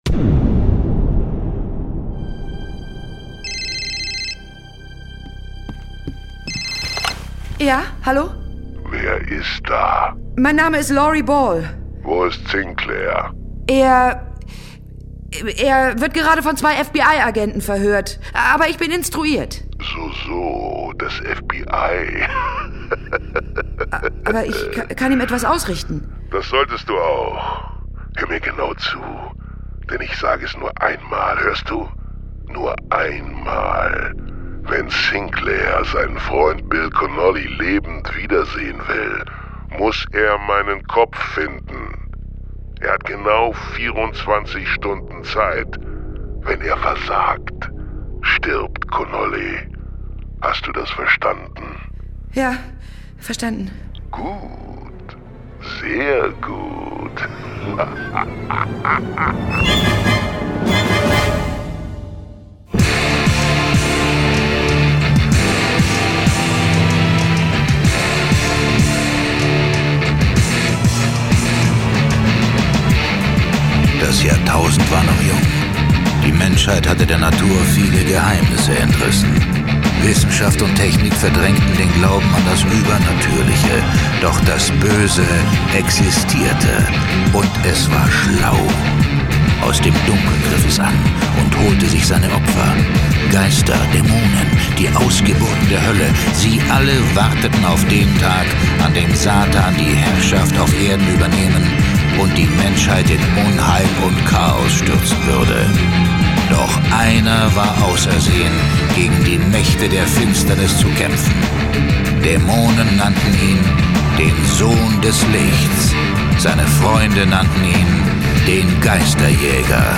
John Sinclair - Folge 17 Bills Hinrichtung. Hörspiel.